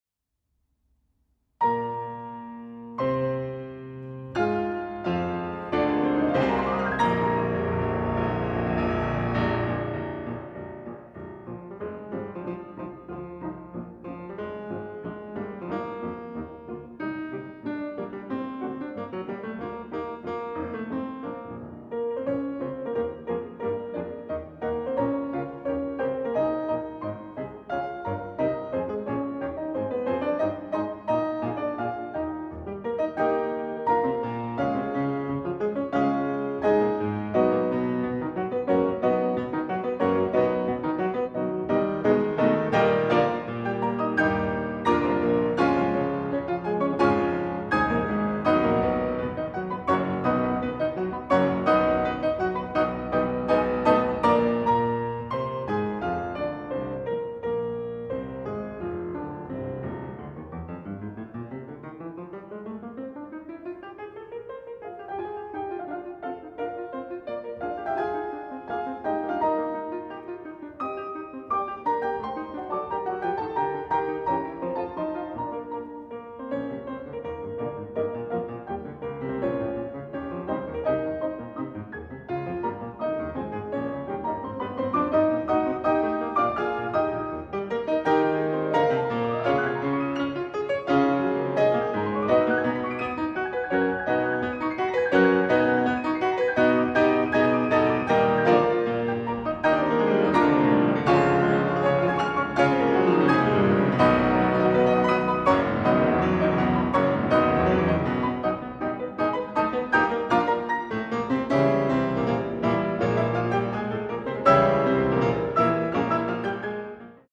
arr. for 2 pianos by composer